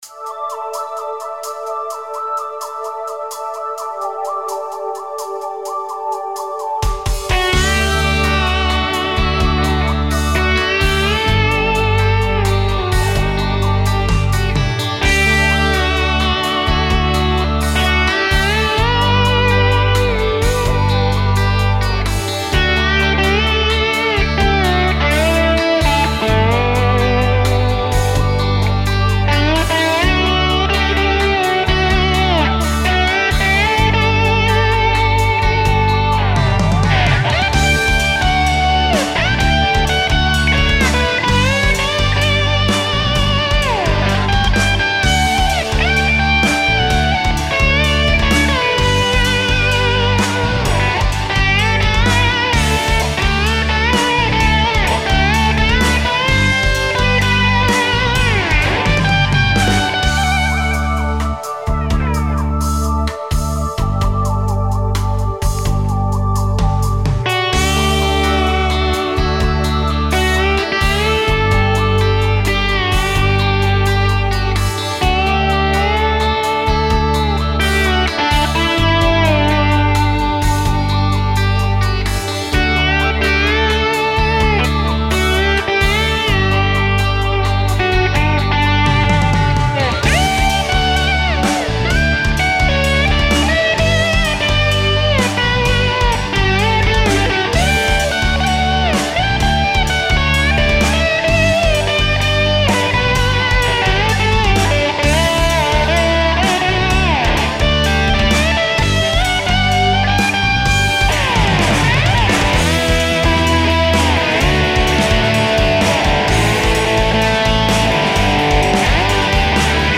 Nette Slide Einlagen. Coole Melodien.
Es klingt eher hektisch und manchmal leicht zittrig im Gegensatz zu Deinem relaxten Feeling in den Lines.